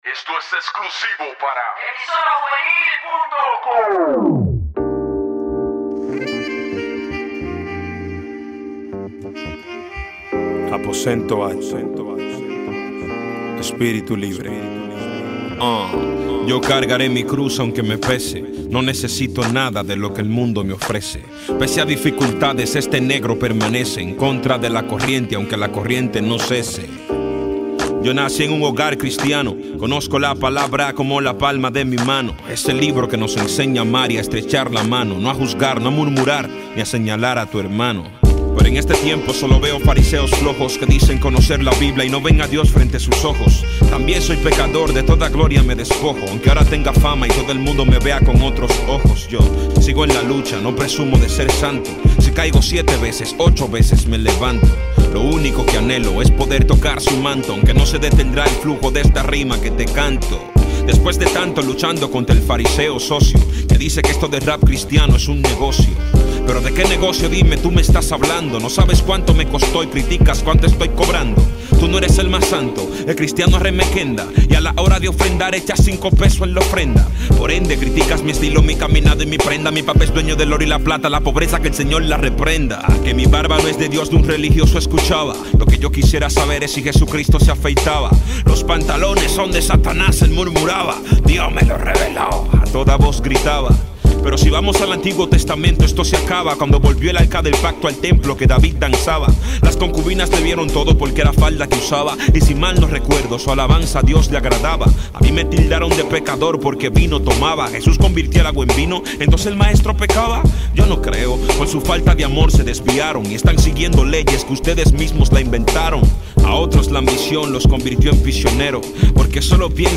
Musica Cristiana
música urbana